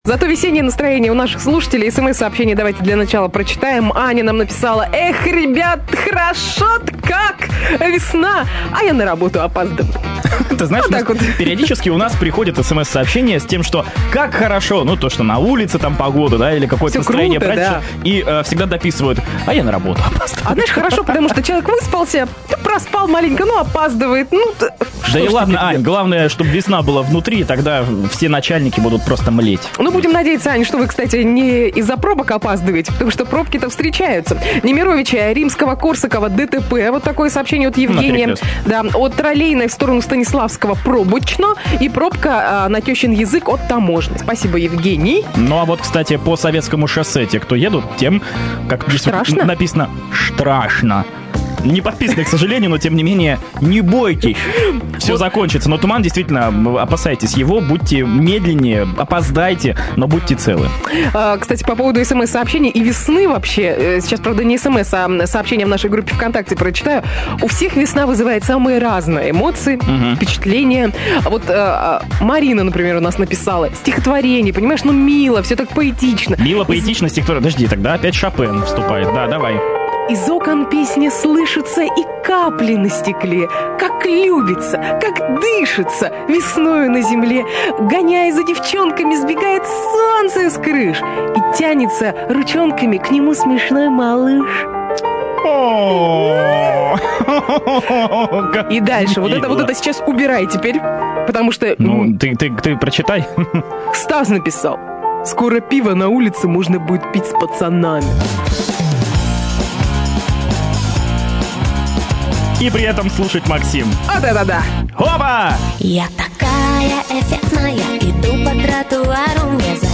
С утра по радио (Юнитон, Новосибирск). Запись эфира.
утреннее шоу
запись эфира